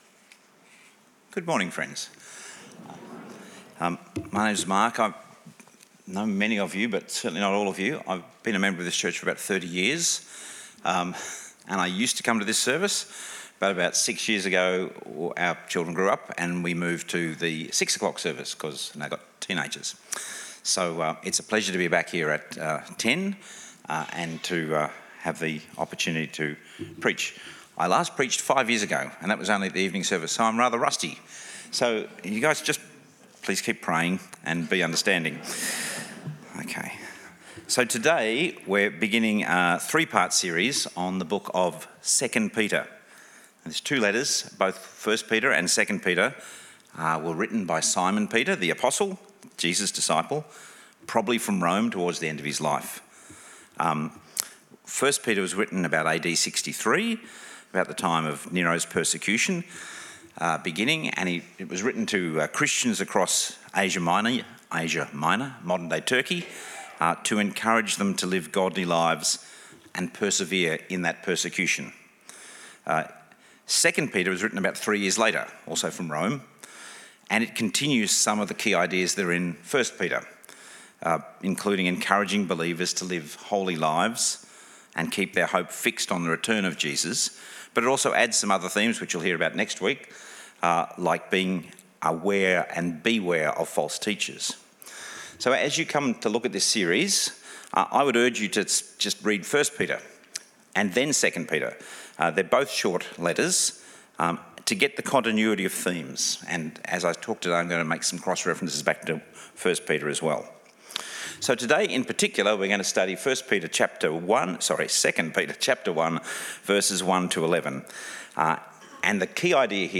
Sermons | St Alfred's Anglican Church
Guest Speaker